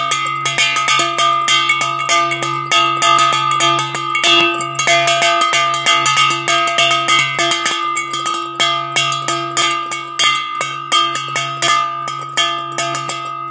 Velký kovový zvon na zavěšení bílý 18x28cm
Kovový zvoneček na zavěšení v bílém provedení Zvonky zkrátka patří k útuln&